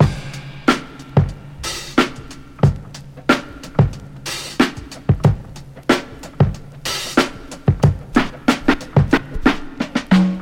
• 92 Bpm Breakbeat Sample F# Key.wav
Free drum loop - kick tuned to the F# note. Loudest frequency: 939Hz
92-bpm-breakbeat-sample-f-sharp-key-YI9.wav